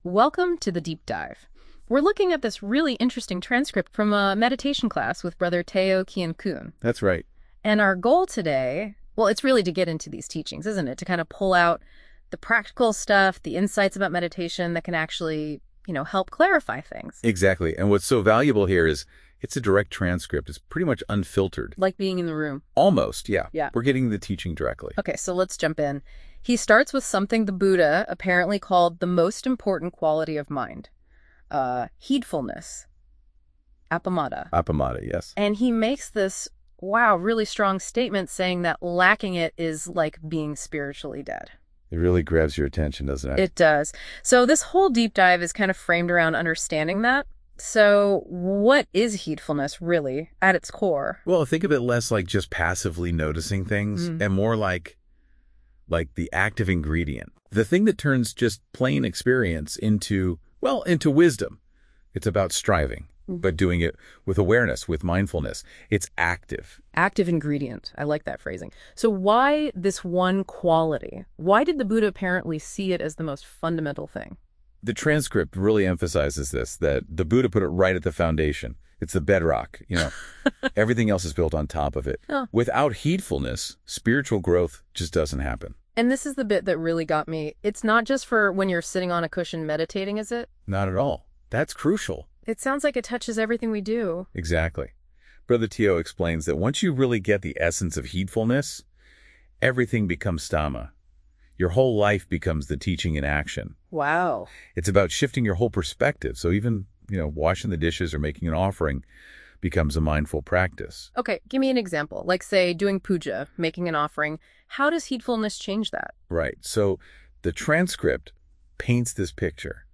AI-Important-Meditation-Instructions-needed-to-understand-the-Meditation-as-taught-by-The-Buddha.mp3